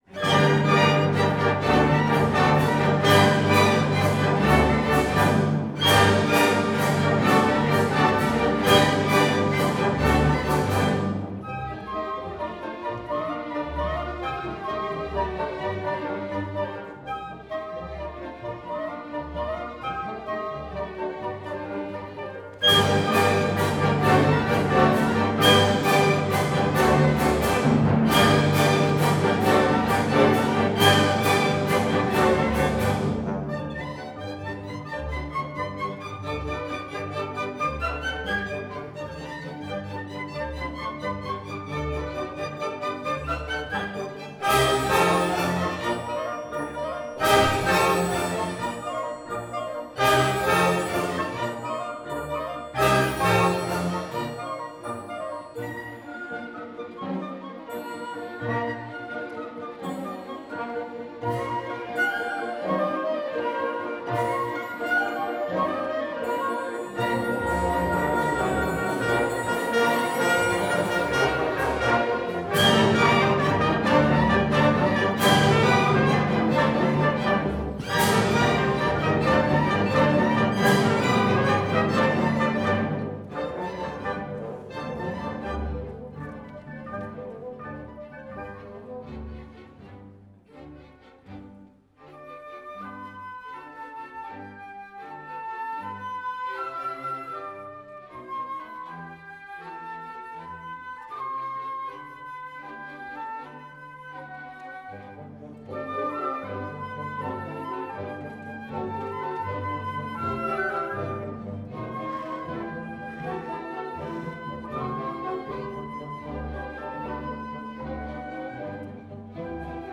Live-Mitschnitt: 12.10.2025, Dreieinigkeitskirche, Berlin - Neukölln